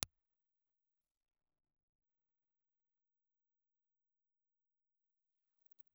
Ribbon
Impulse Response file of the BBC Marconi type B ribbon microphone.